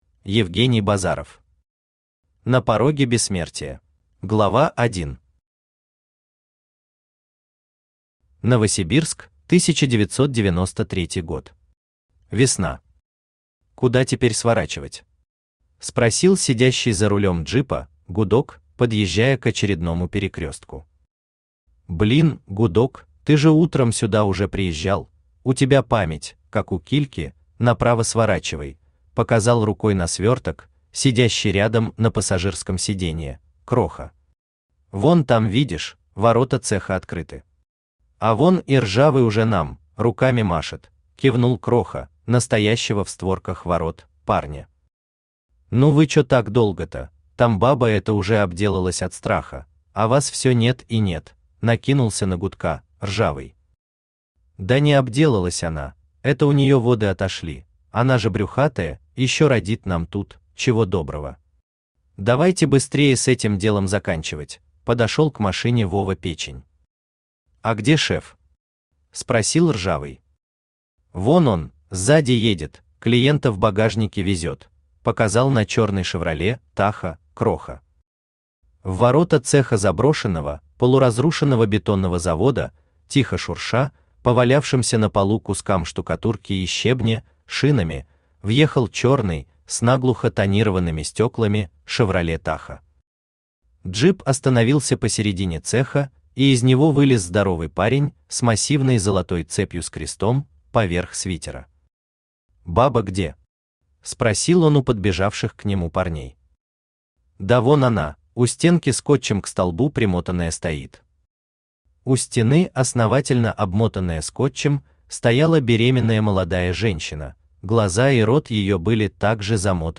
Aудиокнига На пороге бессмертия Автор Евгений Базаров Читает аудиокнигу Авточтец ЛитРес.